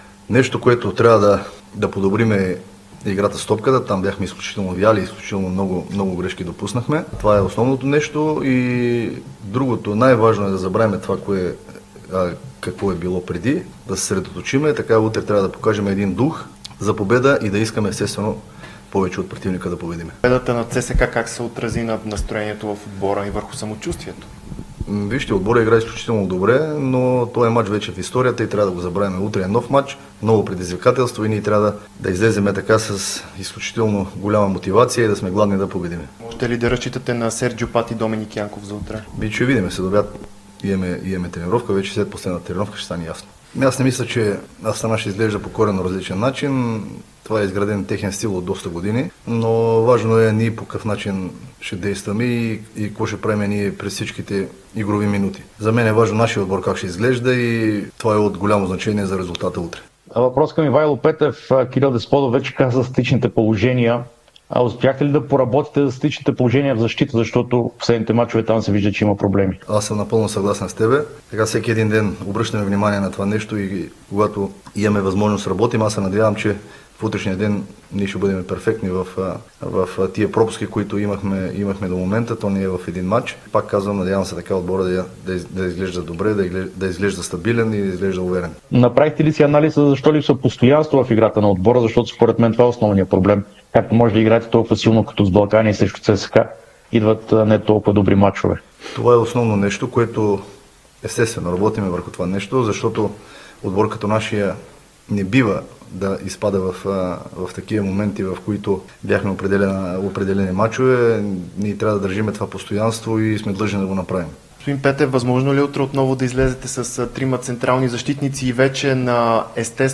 Старши треньорът на Лудогорец Ивайло Петев говори на пресконференцията преди мача срещу Астана от Лига Европа.